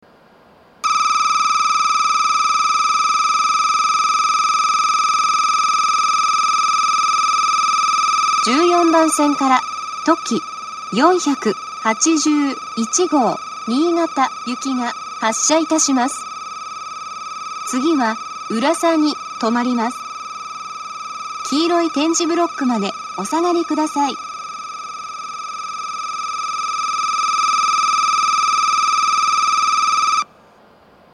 ２０２１年１０月１日にはCOSMOS連動の放送が更新され、HOYA製の合成音声による放送になっています。
１４番線発車ベル とき４８１号新潟行の放送です。